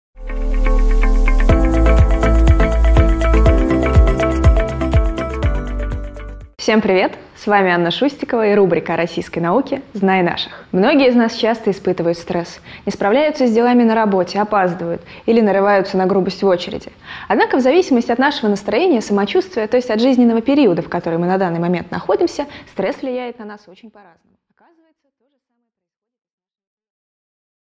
Аудиокнига О тепловом стрессе живучих амебах и поиске экзопланет | Библиотека аудиокниг